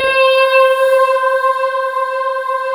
Index of /90_sSampleCDs/USB Soundscan vol.28 - Choir Acoustic & Synth [AKAI] 1CD/Partition D/07-STRATIS